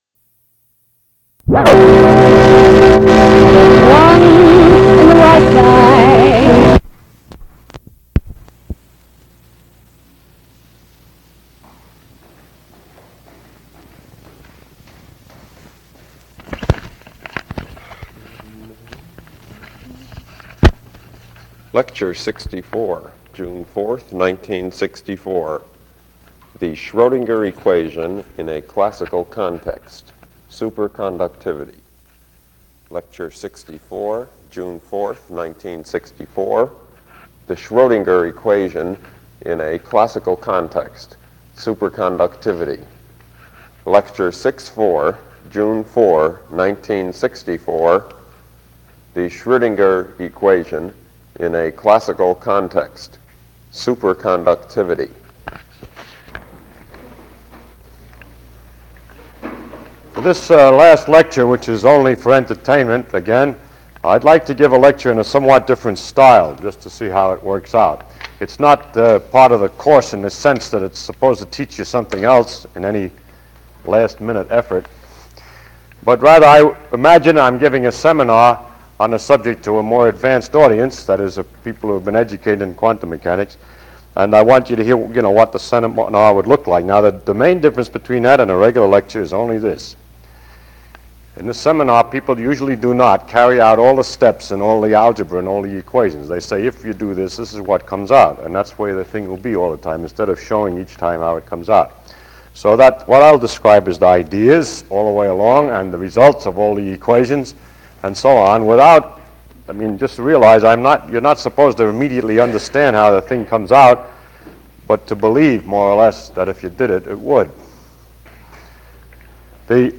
파인만 물리 강의 녹음 18 Angular Momentum